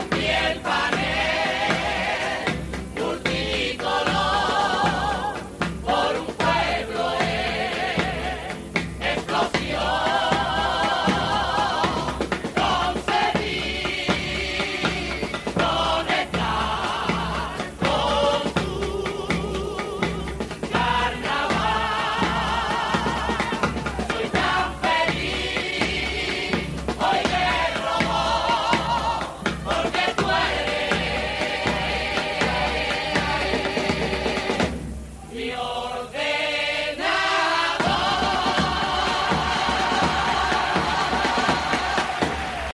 Robots, una de las grandes comparsas que compuso Aurelio del Real